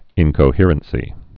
(ĭnkō-hîrən-sē)